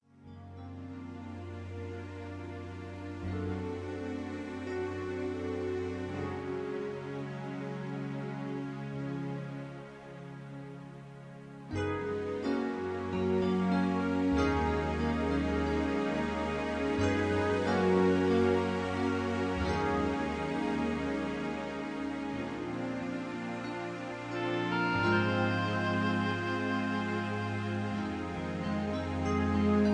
(Key-Gb-Ab-D) Karaoke MP3 Backing Tracks
Just Plain & Simply "GREAT MUSIC" (No Lyrics).